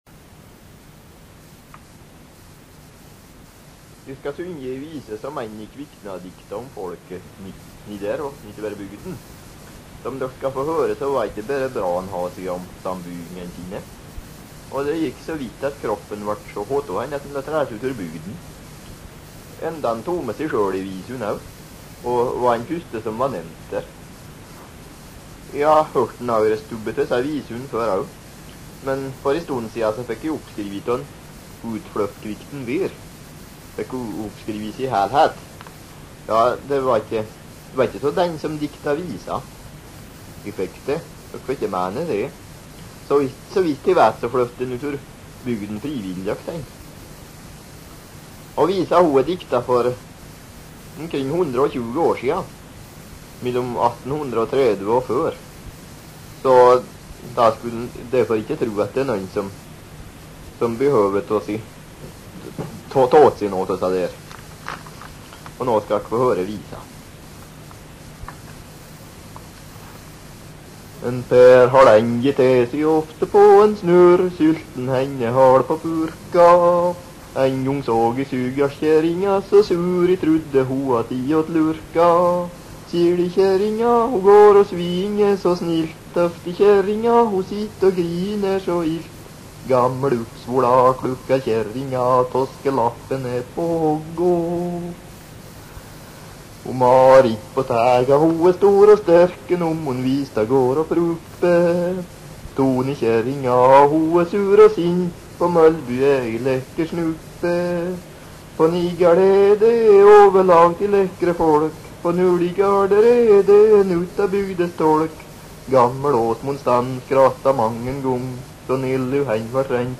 Skjemtevise frå Kvikne - Fron Historielag